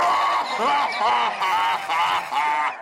Play Vay Hek Laugh - SoundBoardGuy
vay-hek-laugh.mp3